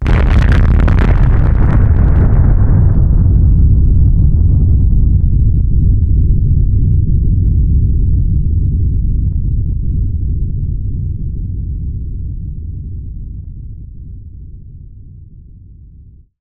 nuclear_detonation_close_proximity.ogg